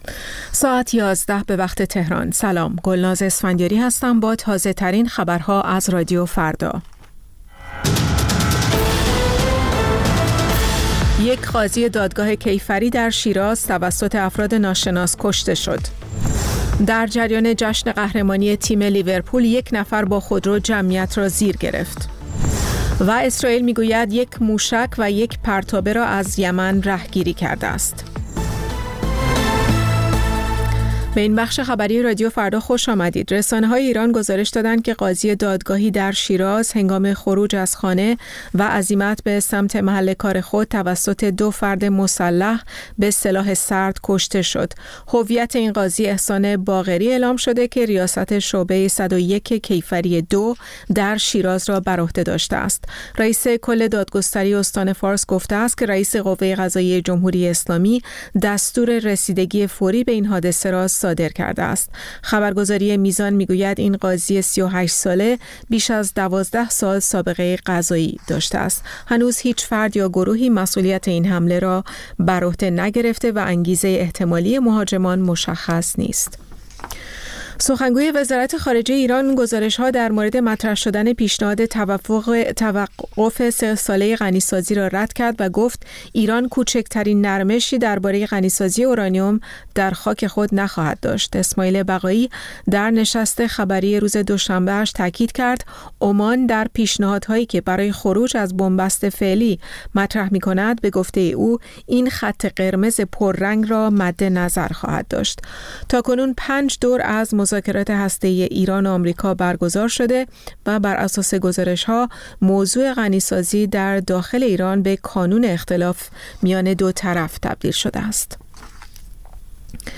پخش زنده - پخش رادیویی